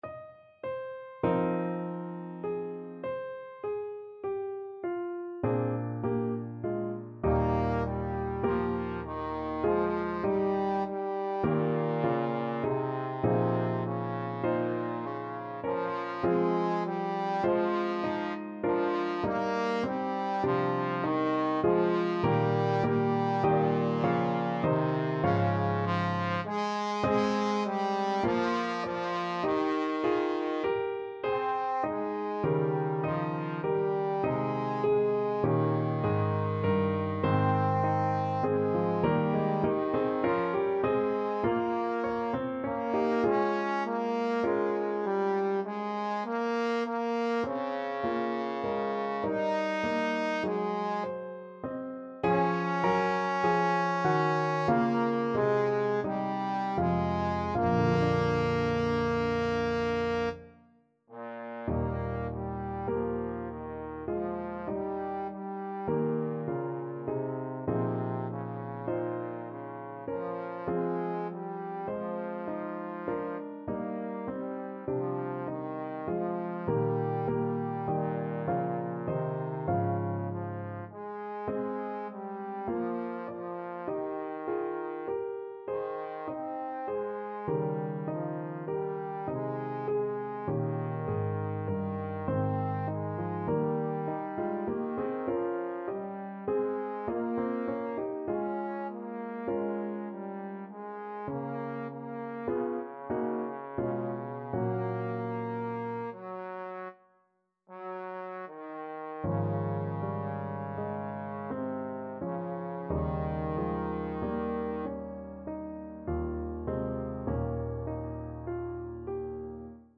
Trombone version
5/4 (View more 5/4 Music)
Bb3-Eb5
Classical (View more Classical Trombone Music)